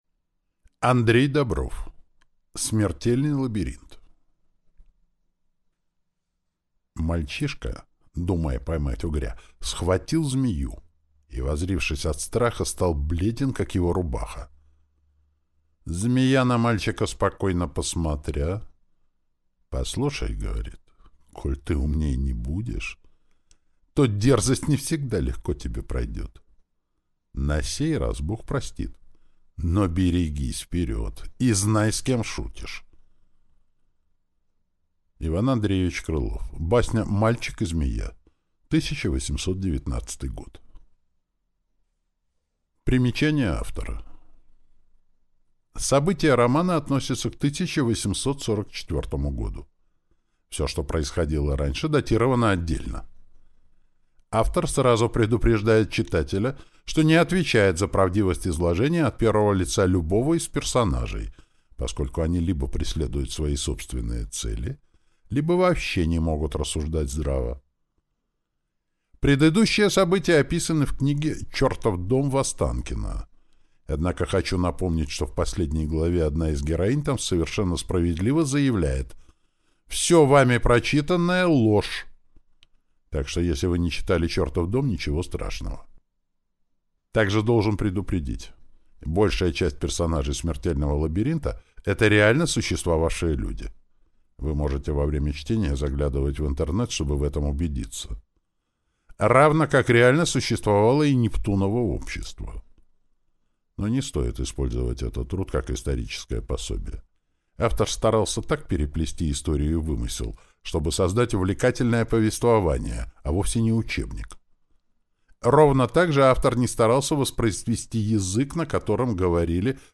Aудиокнига Смертельный лабиринт